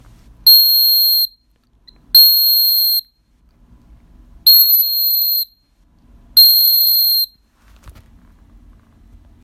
Свисток Devana для подзыва собак из рога косули - купить в Москве
Свисток для подзыва собаки, Чехия. Ручная работа, изготовлен из рога косули.